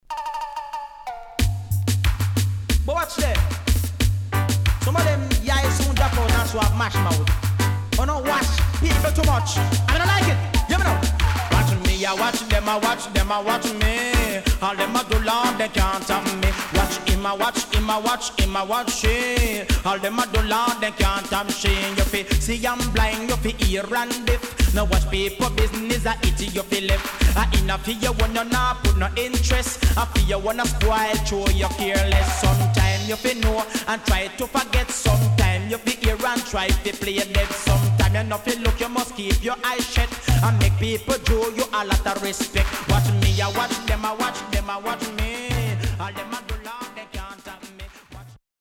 HOME > Back Order [DANCEHALL DISCO45]  >  KILLER